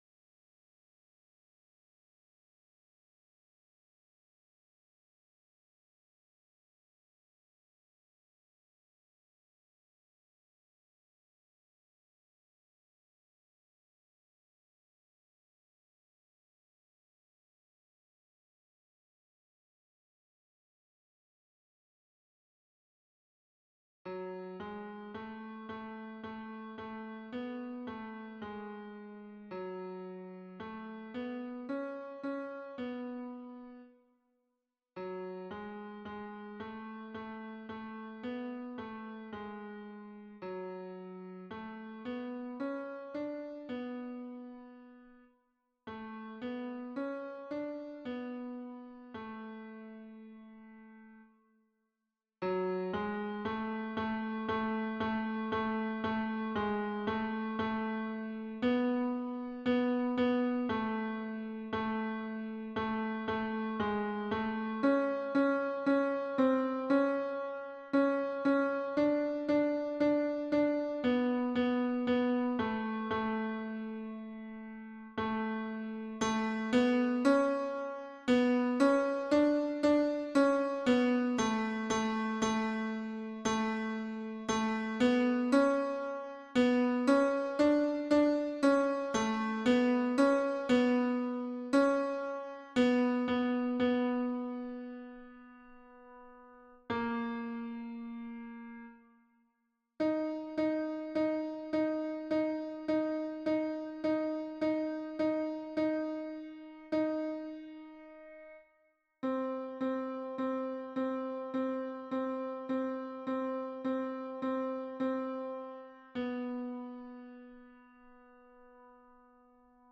MP3 version piano
Ténor